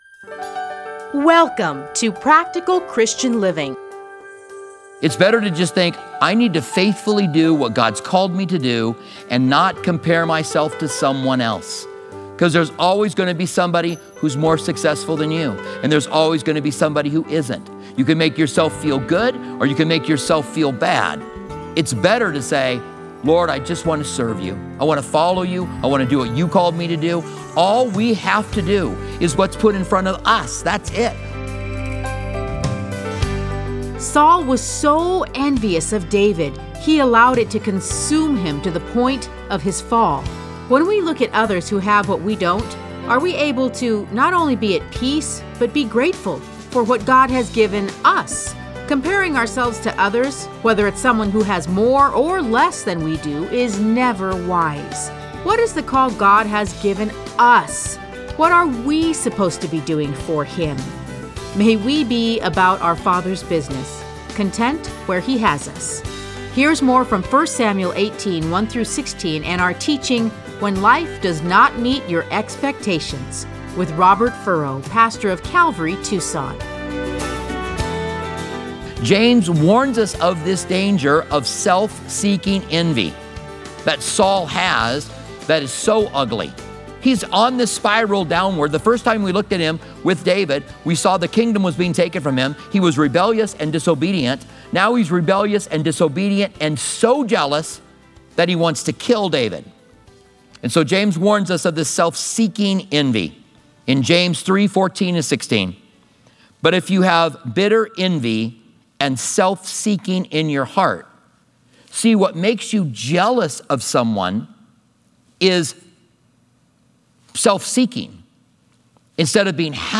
Listen to a teaching from 1 Samuel 18:1-16.